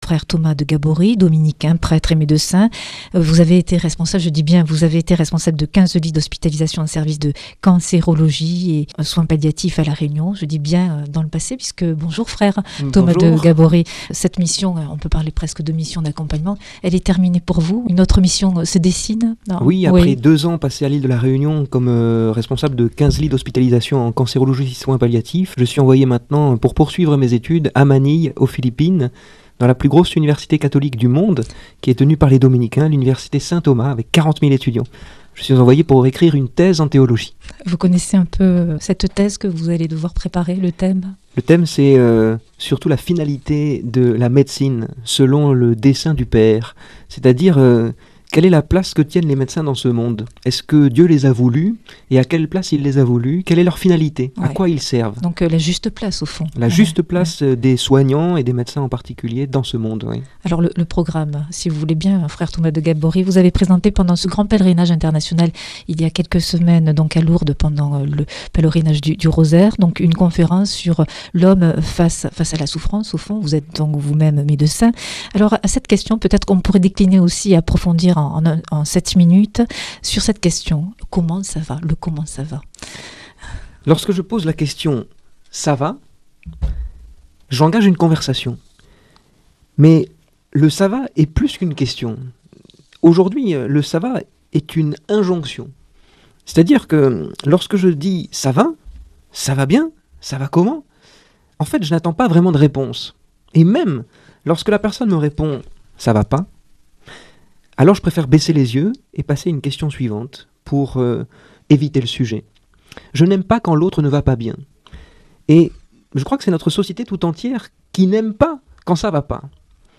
Entretien 3